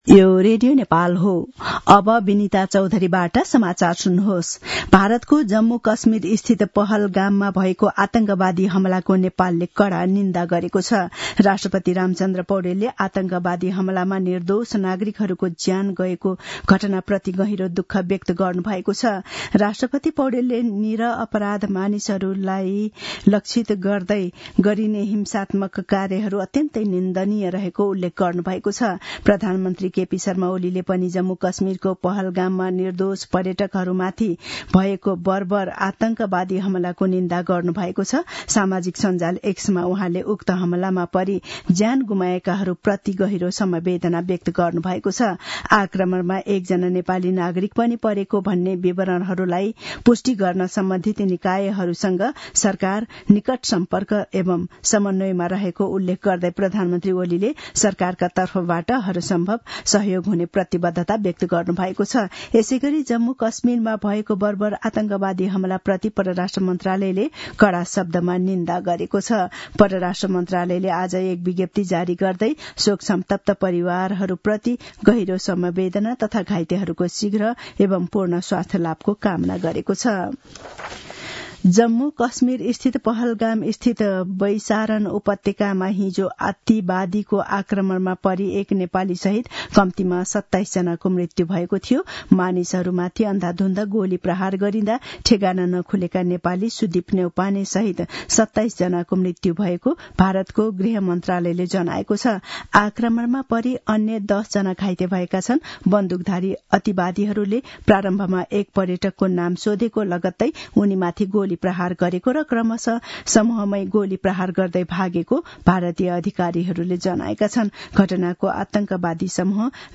दिउँसो १ बजेको नेपाली समाचार : १० वैशाख , २०८२
1-pm-news-1-11.mp3